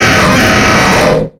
Cri de Galeking dans Pokémon X et Y.